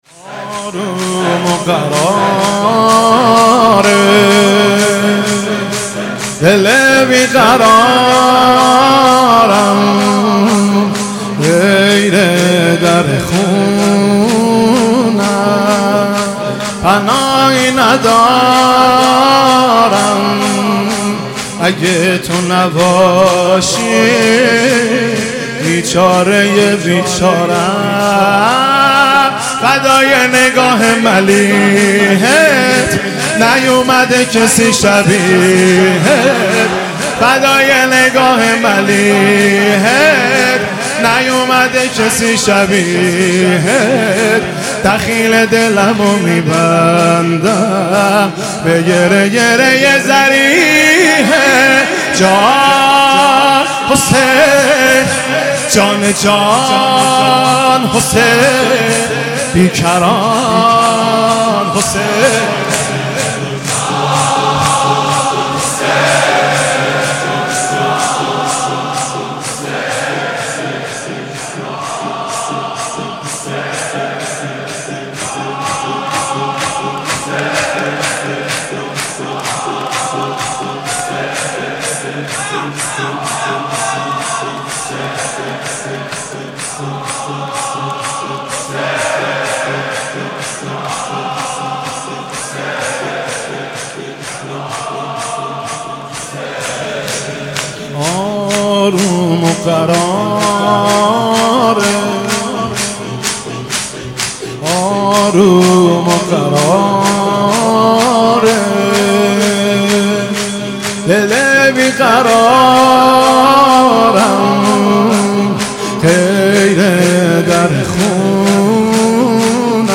محرم1402 شب سوم -شور - آروم و قرار دل بیقرارم - مهدی رسولی
محرم1402 شب سوم